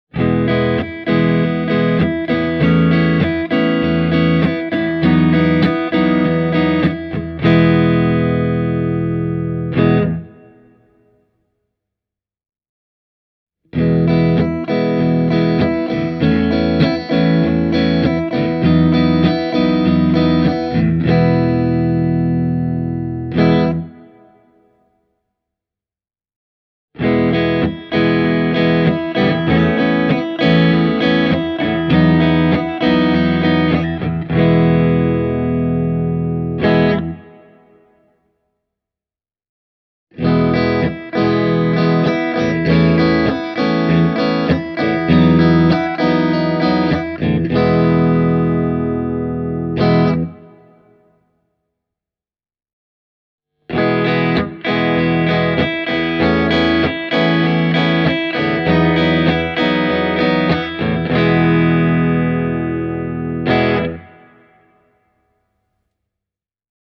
Testasin Carvin Legacy 3 -nupin vaahterakaulaisella Fender Stratocasterilla ja Hamer USA Studio Custom -kitaralla Zilla Cabsin 2 x 12” -kaapin kautta, ja tulokset puhuvat hyvin selkeää kieltä – tässä on kyseessä pro-luokan vahvistin.
Legacyn puhdas kanava on erittäin lähellä omaa ihanne-clean-soundia – sointi on lämmin, avoin ja putipuhdas.
Fender Stratocaster – kanava 1